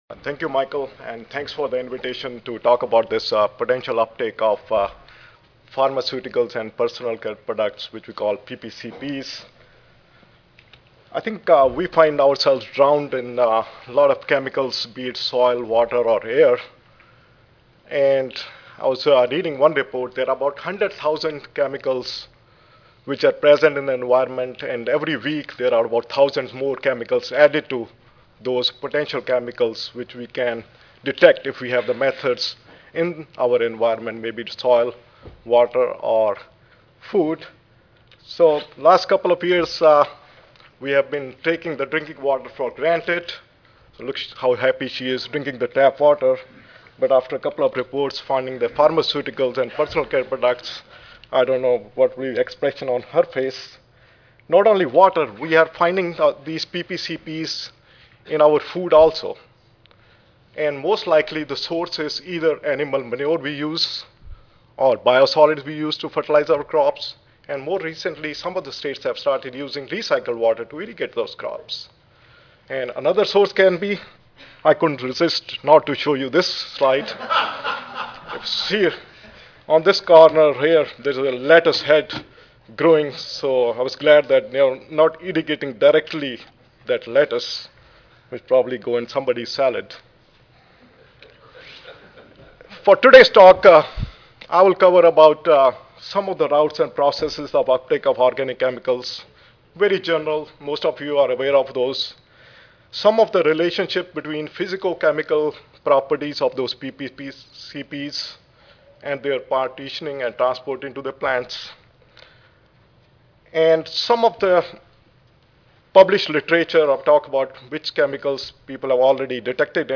Dist. of Greater Chicago Audio File Recorded presentation